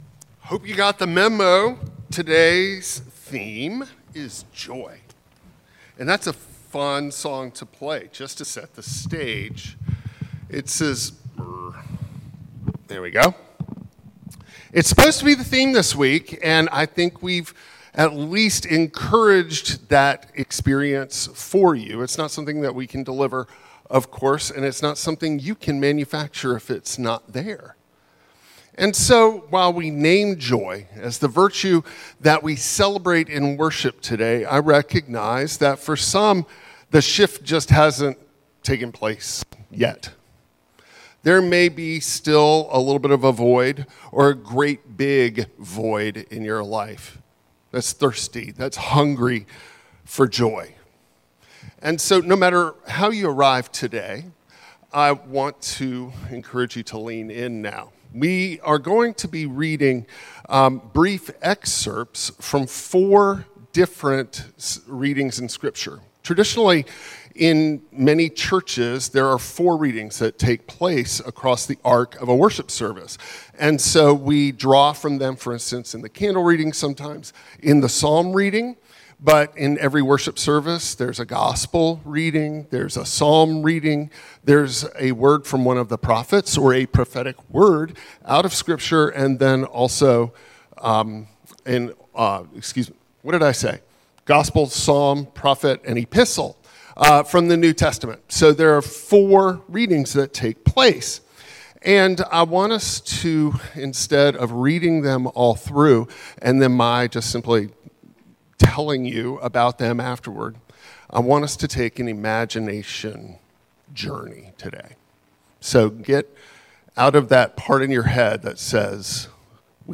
An Advent sermon on joy as patient hope, God’s mercy in exile, and the highway God clears through waiting, justice, and grace.